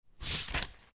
turning_page.mp3